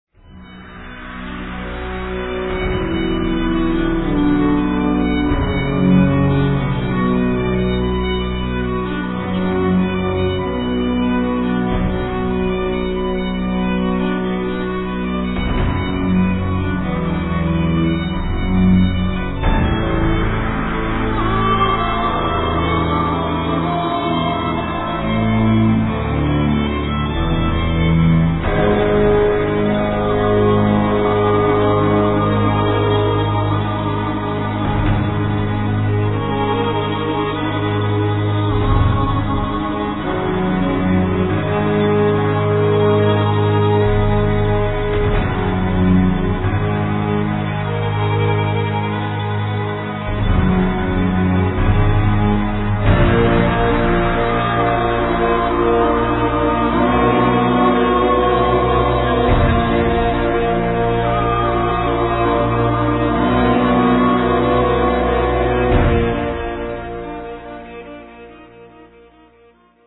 イタリア発、よりドラマチックに、深遠に、ミステリアスに2010年2ndアルバム
double bass, cello, keyboards, bardic harp, programming
voice, flute, hammer dulcimer
celtic harp
violin, viola
basson
classical guitar